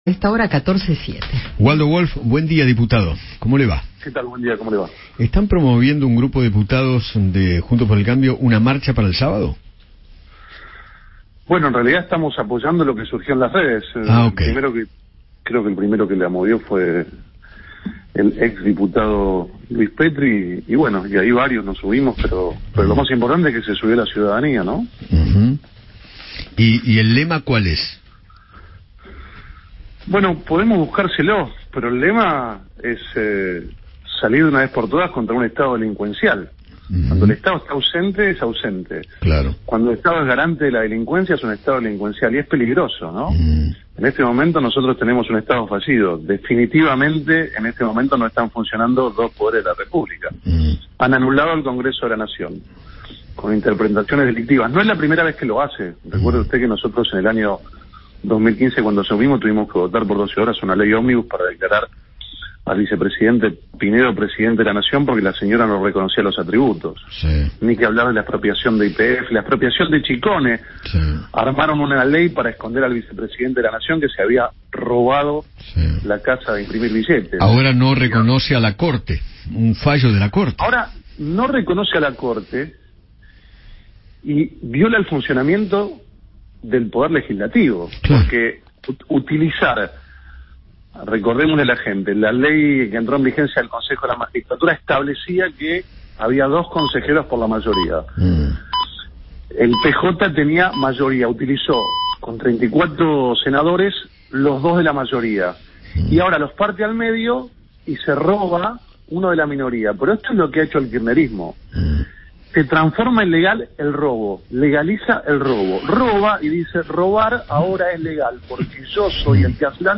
Waldo Wolff, diputado de Juntos por el Cambio, conversó con Eduardo Feinmann sobre la polémica determinación que tomó el oficialismo para conseguir una minoría en el recinto y aseguró que irán a juicio político contra Sergio Massa y Cristina Kirchner.